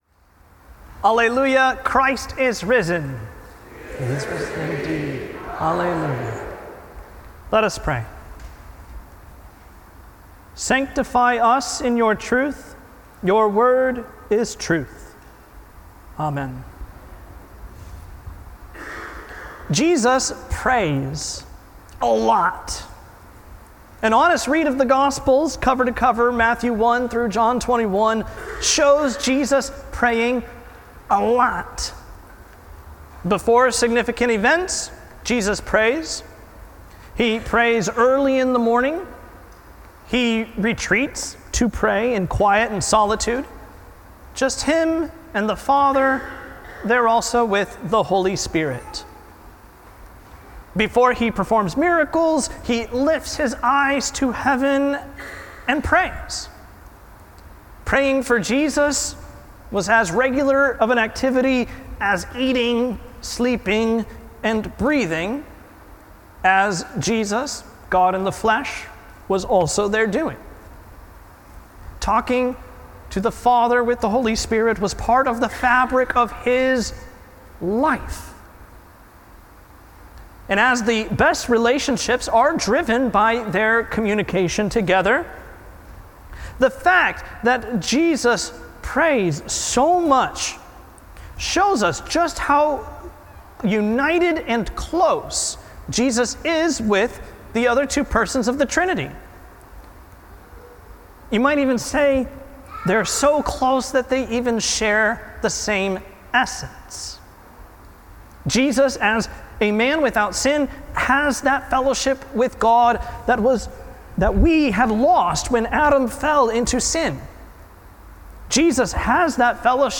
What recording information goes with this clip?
The Sixth Sunday in Easter – Rogate